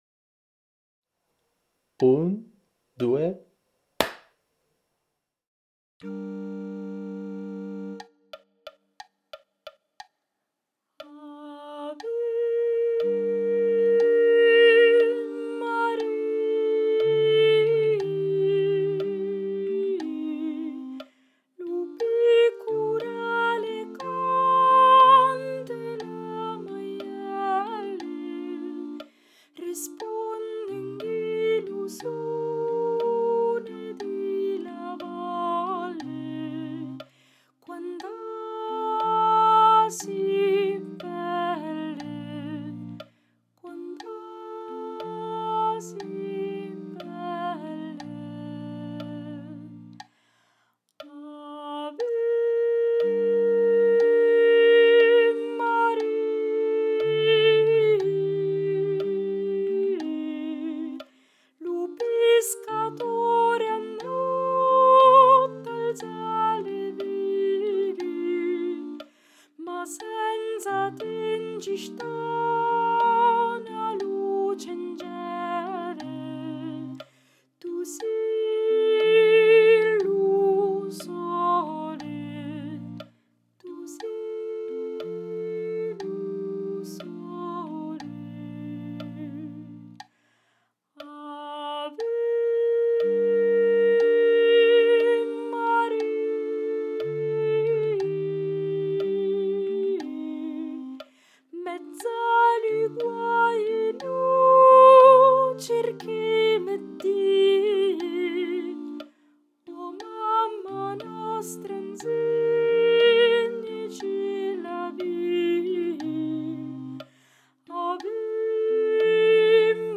🎧 Voce guida
Soprani
• ⚠ Nella traccia guida sentirai una battuta con il tempo a voce, nel "battere" successivo il ciak di inizio (batti una volta le mani come in un "ciak cinematografico"). Avrai un'altra battuta vuota nella quale sentirai l'intonazione della nota di attacco, una ancora di metronomo e quindi inizierai a cantare.